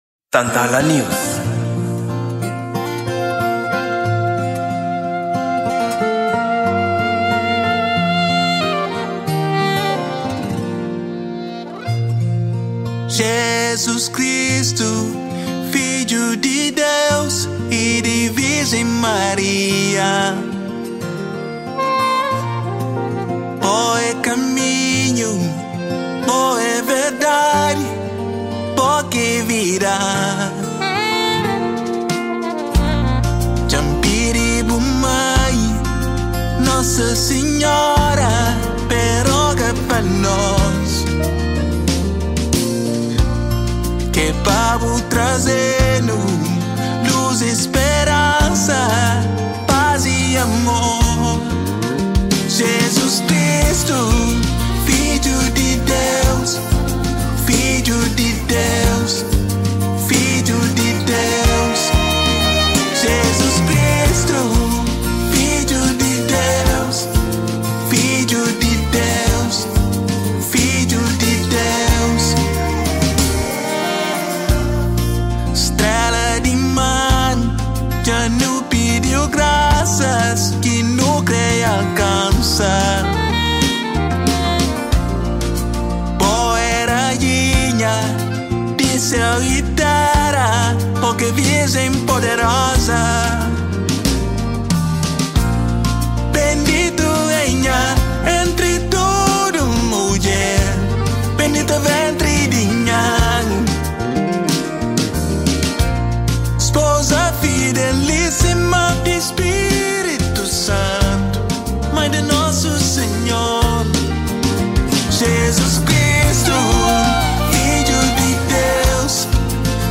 Gênero: Gospel